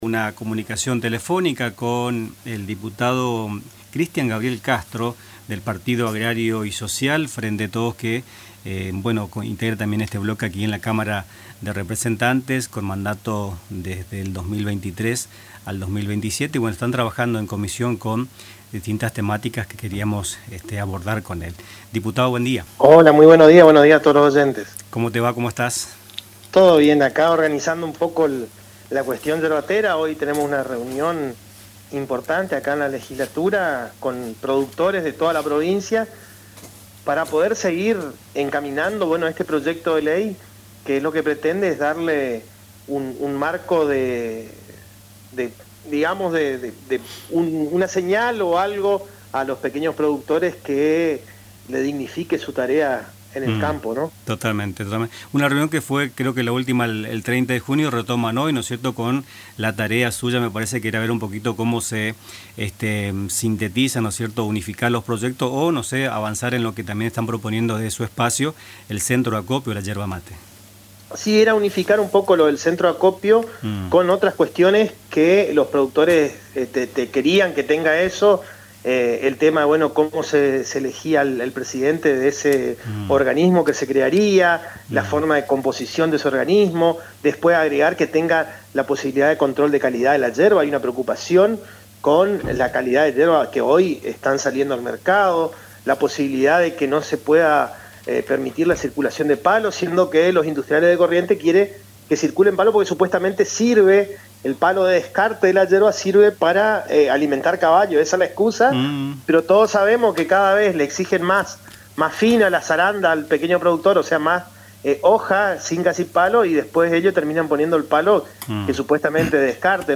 Cristian Castro, diputado provincial del Partido Agrario y Social (PAYS), adelantó en Nuestras Mañanas detalles de la reunión que retoman hoy, para avanzar en la síntesis de un proyecto legislativo que contemple cargos electivos por zonas y presupuestos, como también ahondar en temas que ya los había discutido la histórica CRYM.